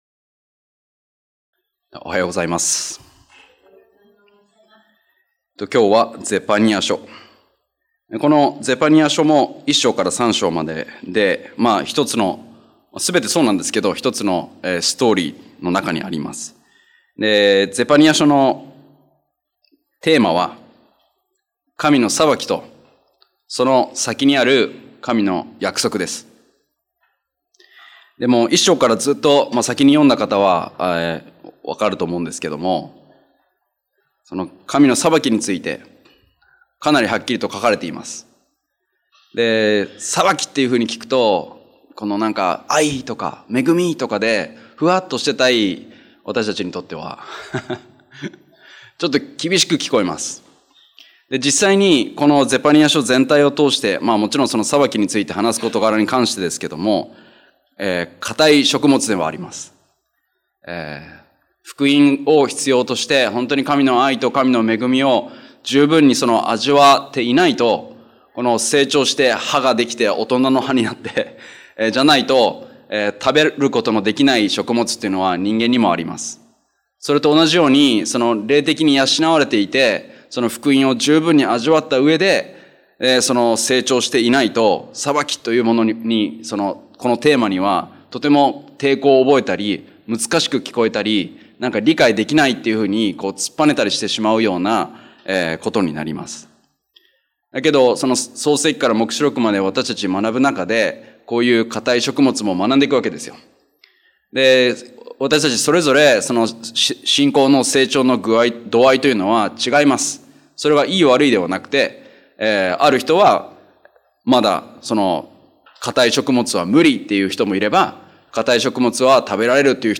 日曜礼拝：ゼパニヤ書
礼拝メッセージ